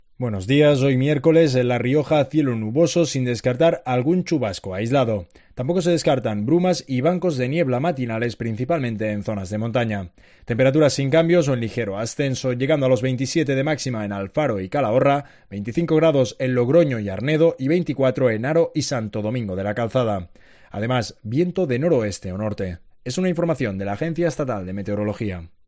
Escucha aquí la previsión del tiempo en La Rioja: Miércoles 13 de septiembre